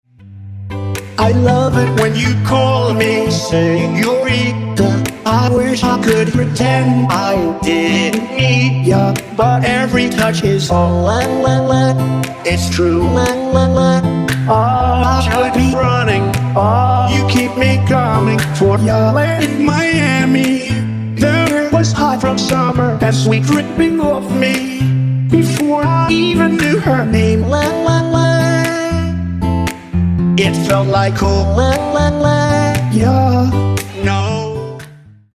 • Качество: 192, Stereo
гитара